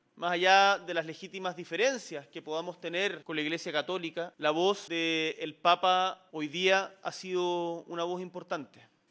Hoy, en pleno Consejo de Gabinete, instancia marcada por el tono político y por la antesala de la gira, el Presidente Boric habló durante cerca de veinte minutos.